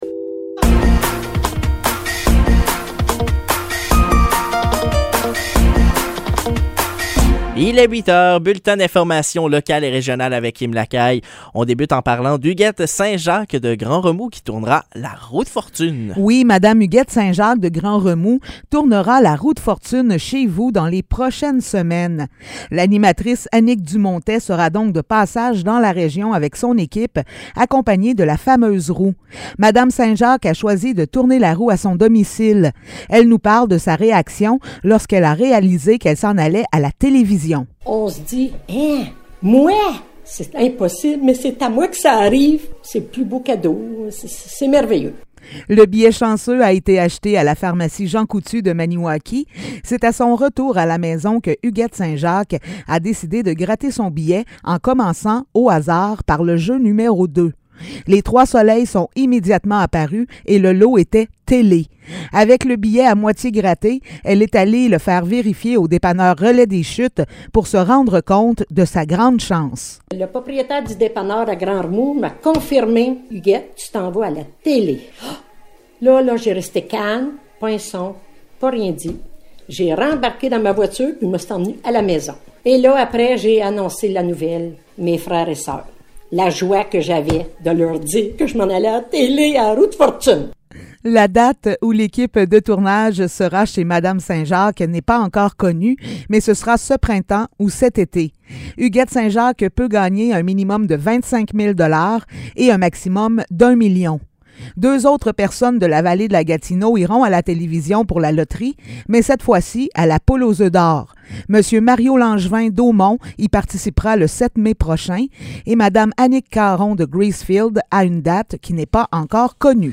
Nouvelles locales - 27 avril 2023 - 8 h